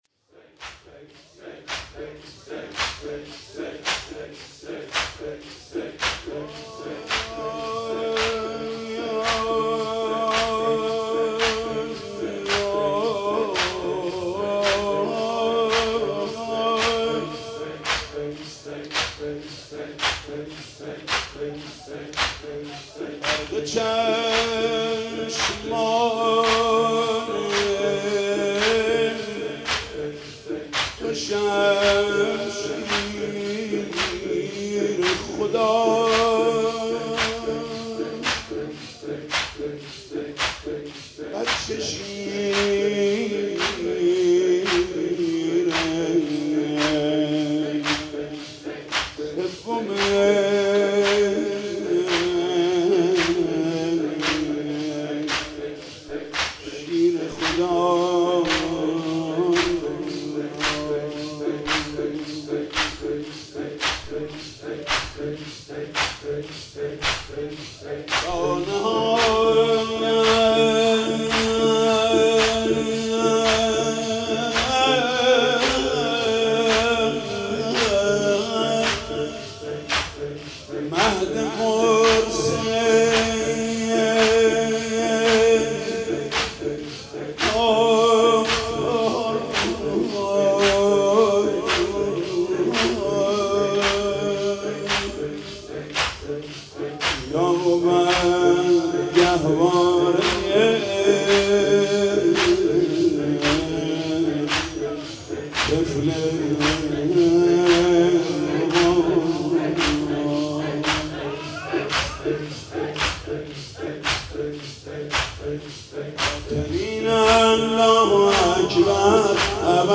مداحی فاطمیه
[سینه زنی در شب شهادت حضرت فاطمه زهرا(س)]